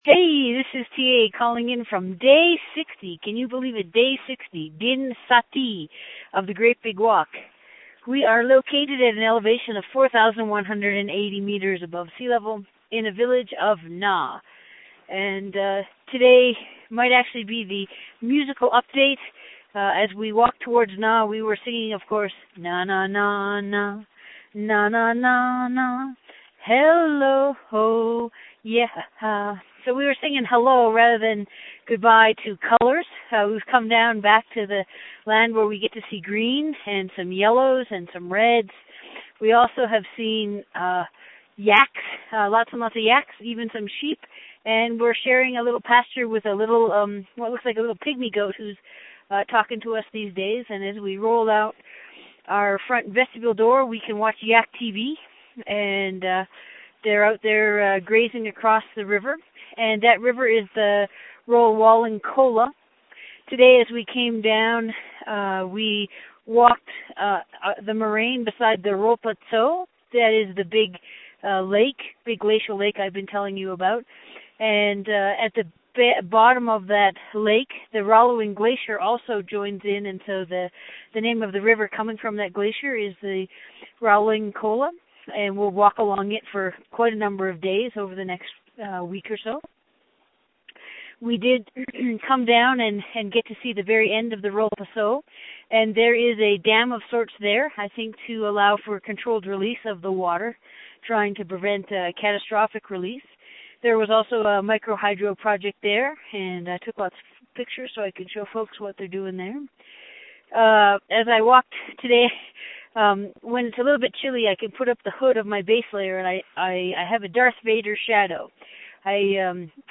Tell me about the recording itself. Great Big Walk acknowledges the support of the Memorial University of Newfoundland Quick Start Fund for Public Engagement in making these updates from the field possible.